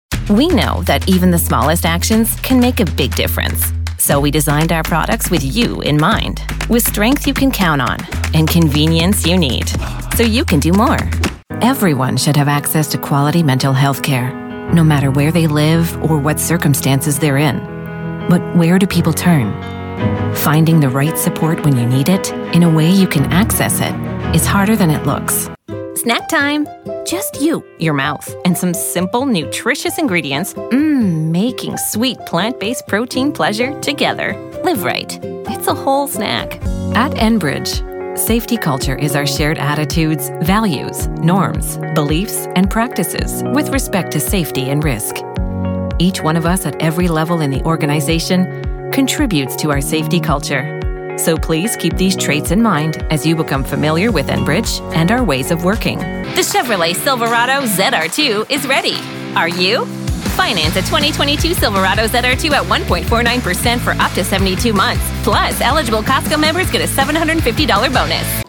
French-Canadian, Female, Home Studio, 20s-40s, Based in Canada